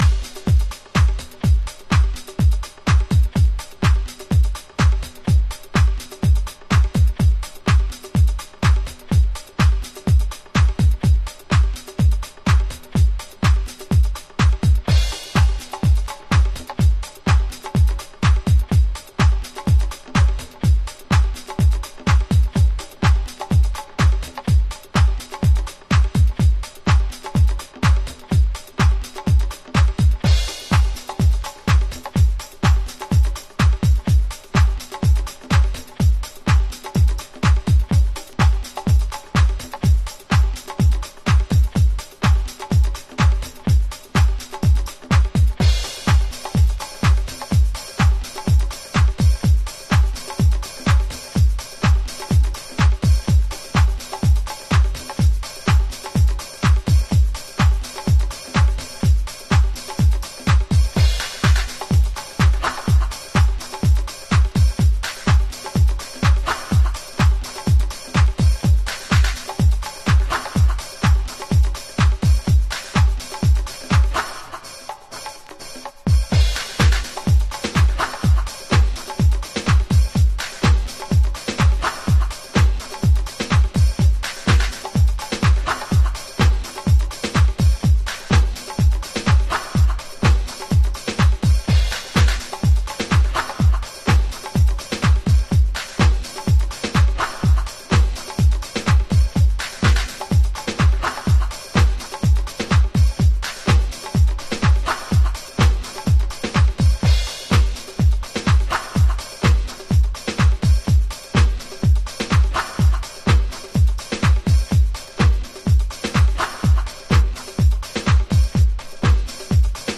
わかっていても足がソワソワするビートに定番のヴォイスサンプルやアコースティックのウォーミー塩梅がバッチリです。